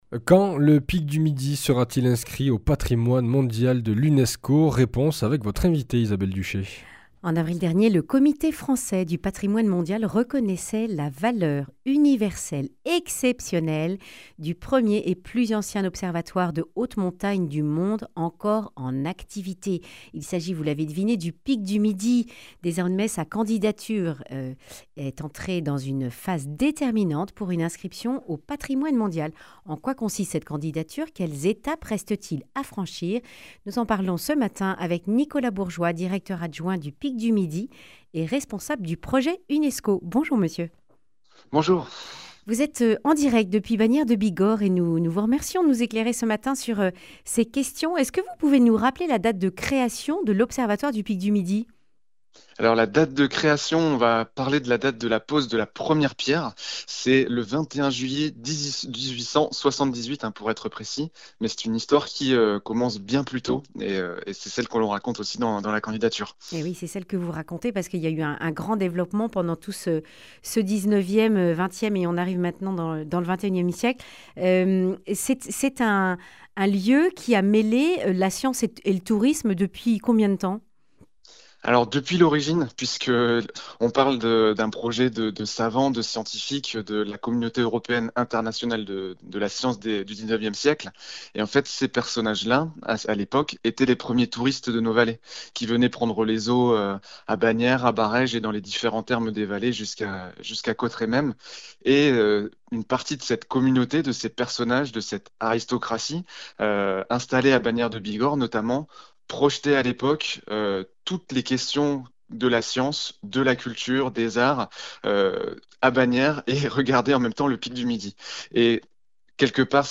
Accueil \ Emissions \ Information \ Régionale \ Le grand entretien \ Le Pic du Midi en cours d’inscription au patrimoine mondial de (...)